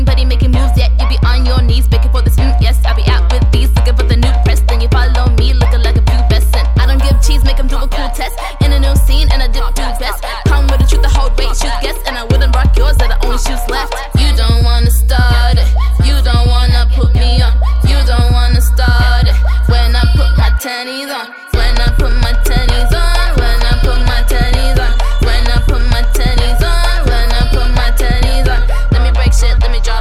la pop, le garage anglais, le hip-hop oldschool, et la trap